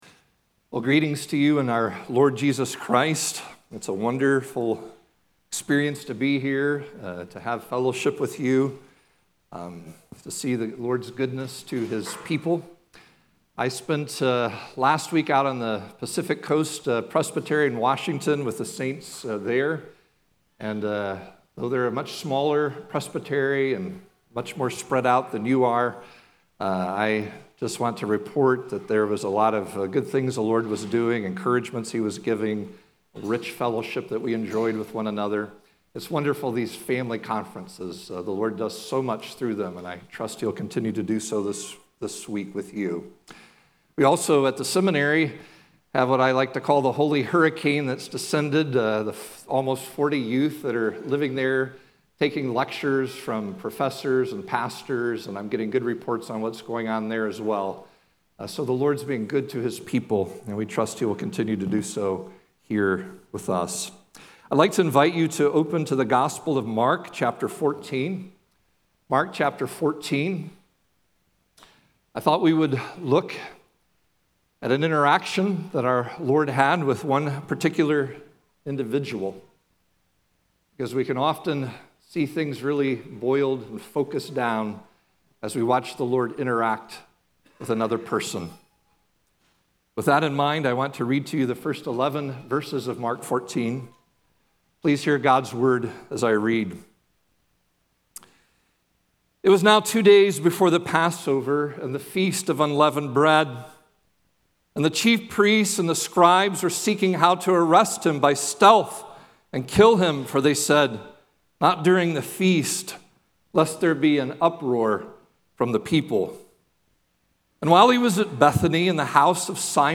Laurelville Consecration Service 2025